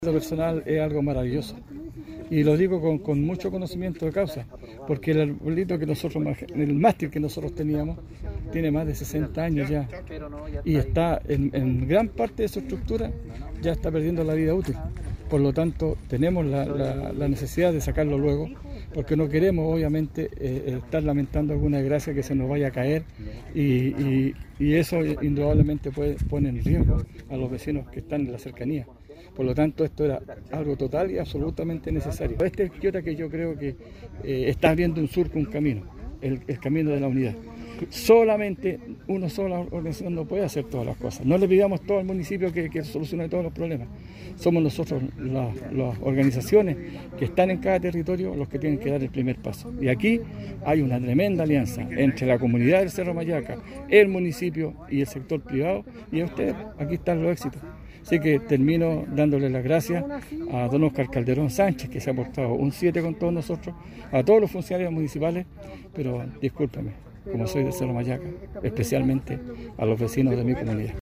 Concejal-Ramon-Balbontin.mp3